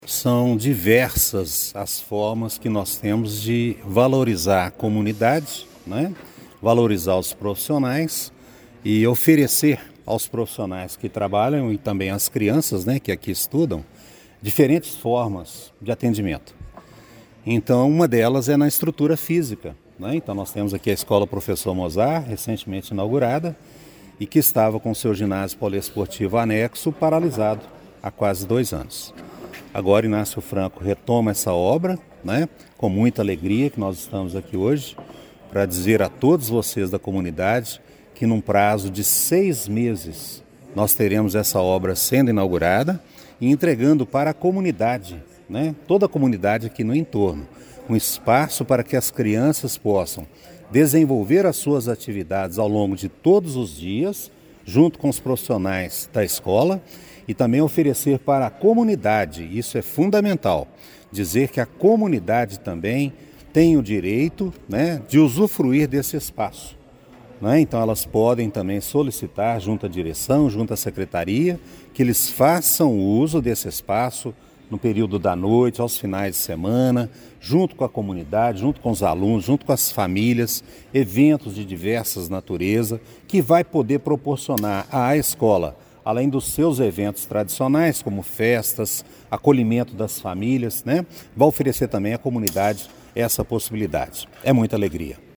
O secretário municipal de Educação, Marcos Aurélio dos Santos, reforçou que a retomada traz alegria para a comunidade, estabelecendo uma meta para o término dos trabalhos: